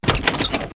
1 channel
doorop04.mp3